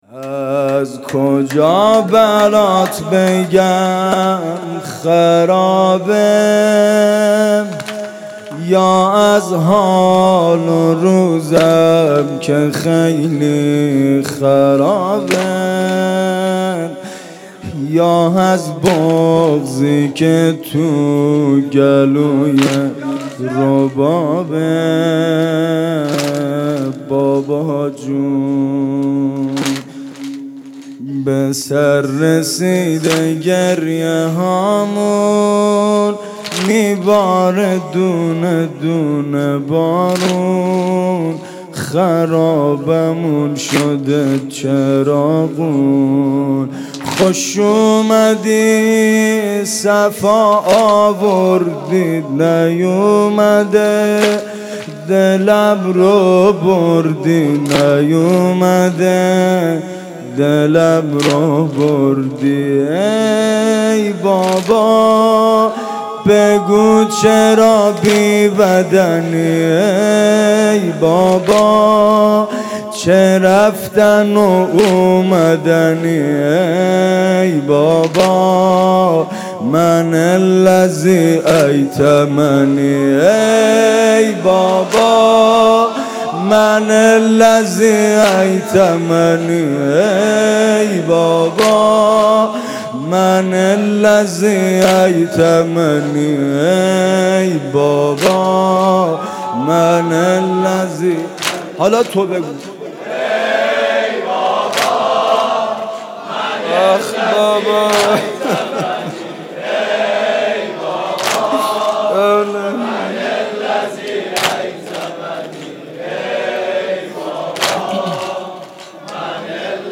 مراسم عزاداری شب سوم محرم الحرام 1441